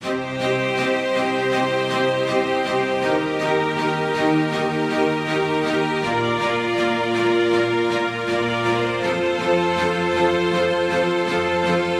悲伤的弦乐第二部分
Tag: 80 bpm Pop Loops Strings Loops 2.02 MB wav Key : Unknown